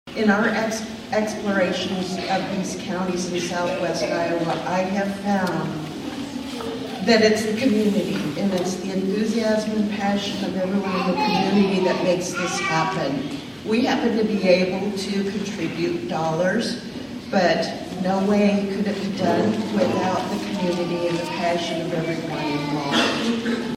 The communities support and involvement in this project was echoed by all who spoke at the ceremony.